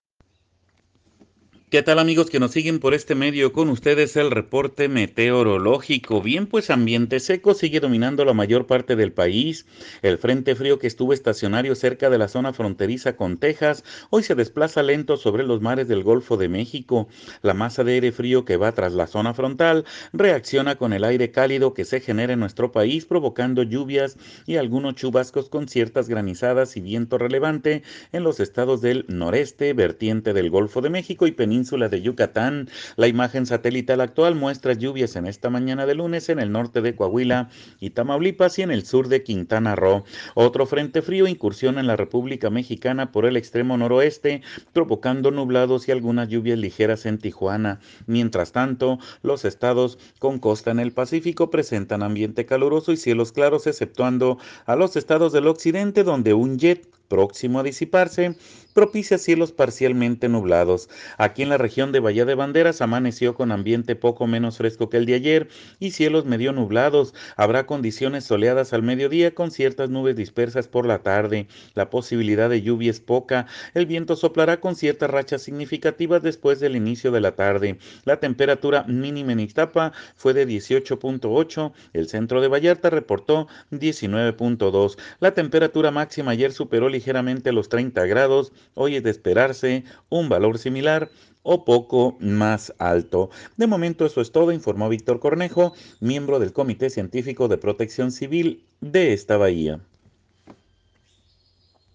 escuche al meteorólogo